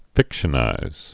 (fĭkshə-nīz)